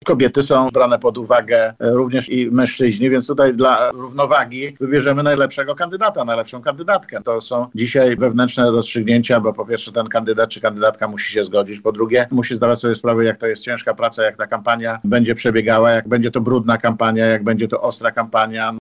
Nazwisko kandydata lub kandydatki Lewicy na prezydenta poznamy 15 grudnia – zapowiada Jacek Czerniak, wiceminister rolnictwa i poseł Nowej Lewicy, który był gościem porannej rozmowy w Radiu Lublin.